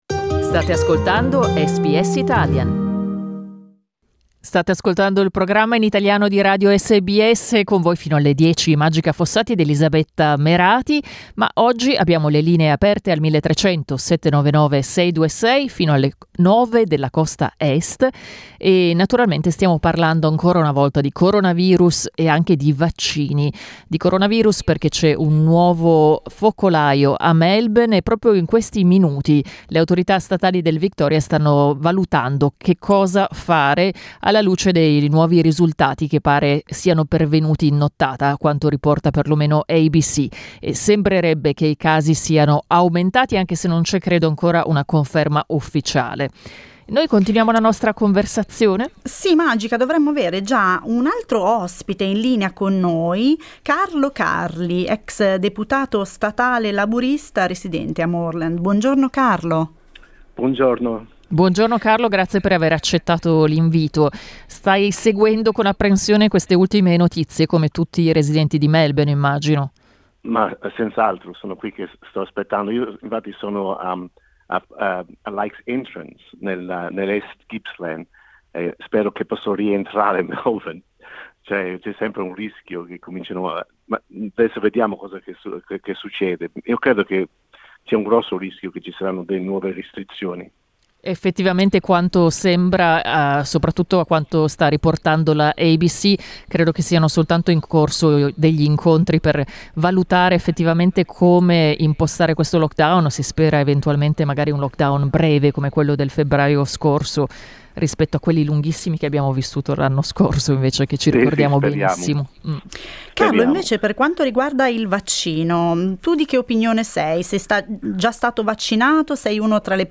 Leggi anche: Annunciato un lockdown di sette giorni nel Victoria Questa mattina abbiamo aperto le linee del nostro programma radiofonico per chiedere ad ascoltatori e ascoltatrici se abbiano intenzione di vaccinarsi, alla luce dei recenti avvenimenti in Victoria. Alcune persone intervenute in diretta si sono dette fiduciose nei confronti delle indicazioni delle autorità sanitarie.